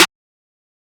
snare 2.wav